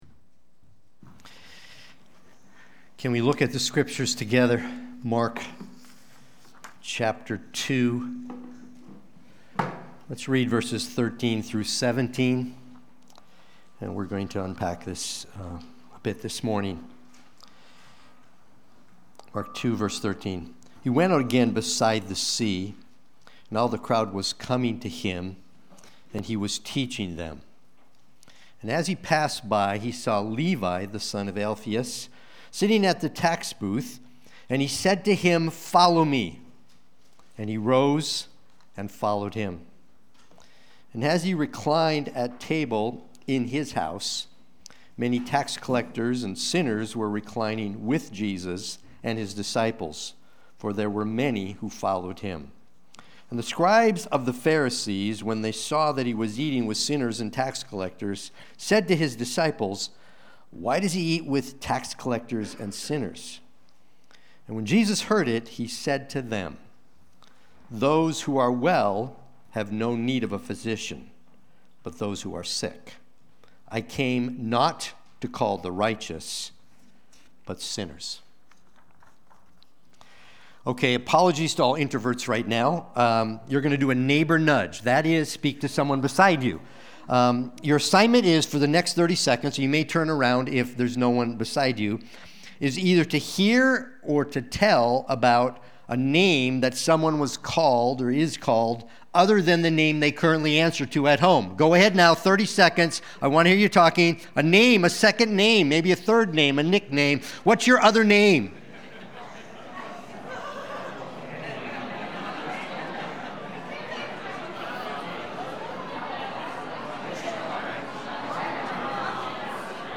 Watch the replay or listen to the sermon.